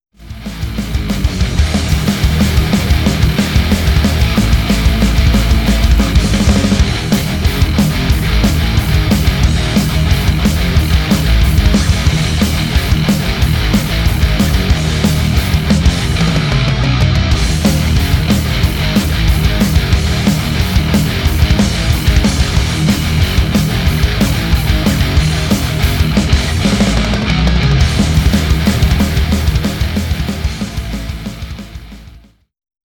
Rock/Metal
Drums without parallel compression